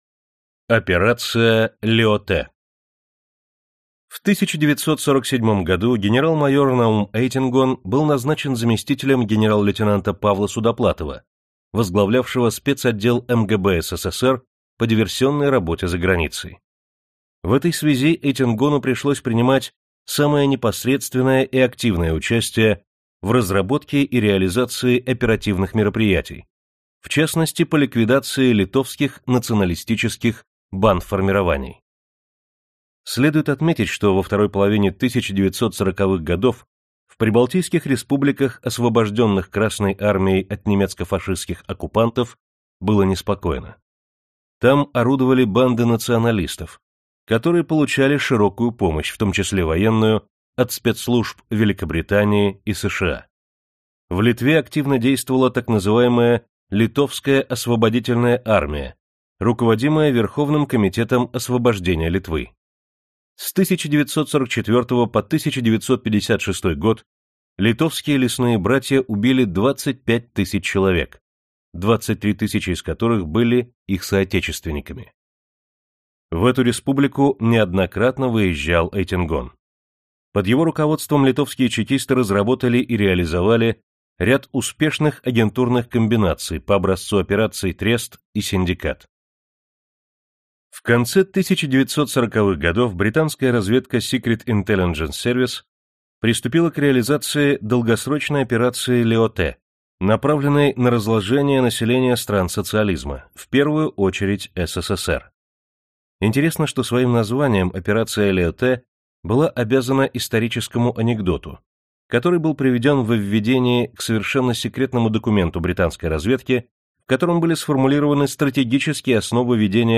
Аудиокнига В окопах холодной войны | Библиотека аудиокниг